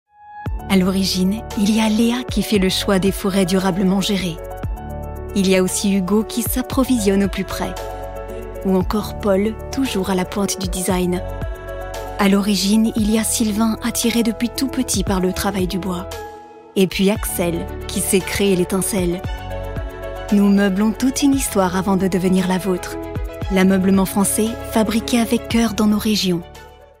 INSTITUTIONNEL – L’AMEUBLEMENT FRANÇAIS : VOIX BIENVEILLANTE
Institutionnel